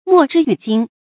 莫之与京 mò zhī yǔ jīng
莫之与京发音
成语注音ㄇㄛˋ ㄓㄧ ㄧㄩˇ ㄐㄧㄥ